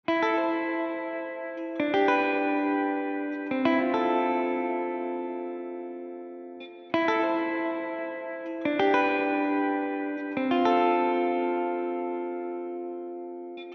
Digital Reverb einstellen — Digitalhall
Im folgenden Soundbeispiel hören Sie eine Gitarre mit einem Digitalhall:
Gitarre-Digital-Hall.mp3